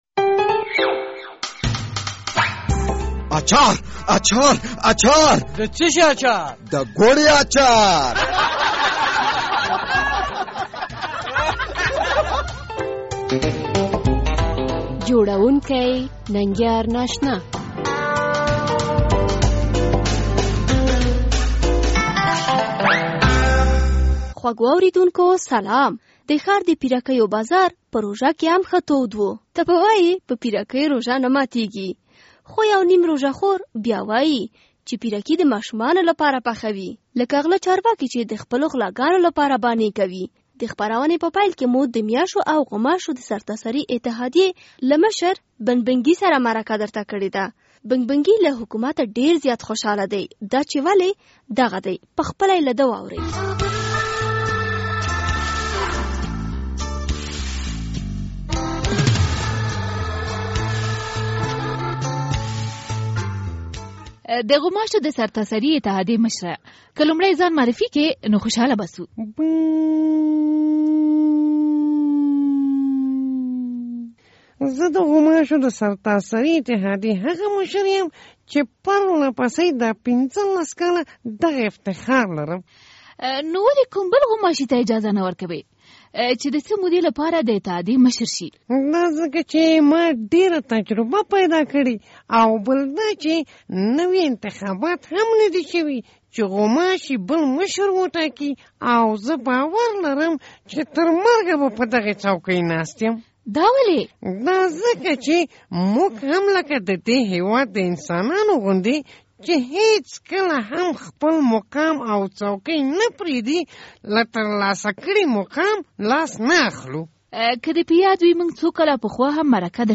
د ګوړې اچار په دې خپرونه کې مو د میاشو او غوماشو د سرتاسري اتحادېې له مشر "بنګ بنګي" سره مرکه کړې ده.